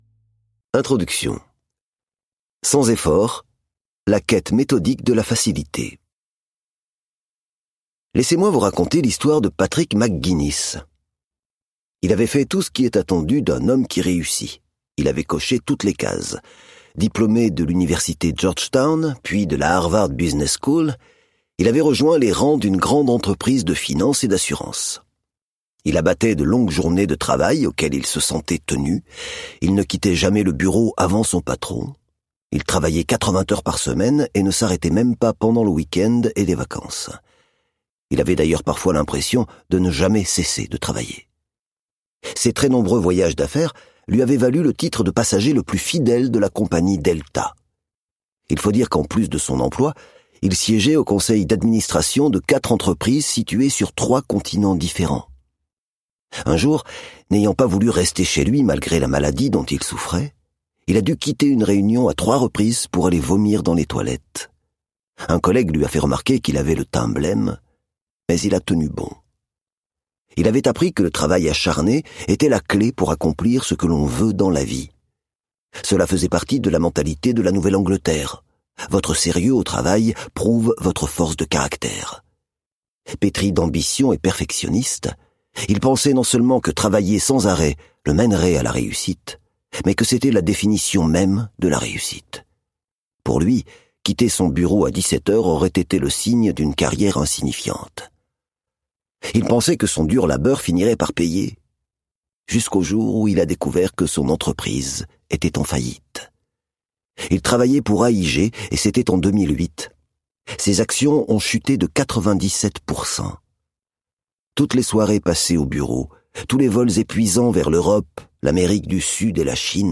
Click for an excerpt - Zéro effort de Greg McKeown